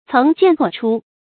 層見錯出 注音： ㄘㄥˊ ㄒㄧㄢˋ ㄘㄨㄛˋ ㄔㄨ 讀音讀法： 意思解釋： 一件件交錯出現.